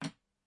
餐具的声音 " 大勺子4
Tag: 餐具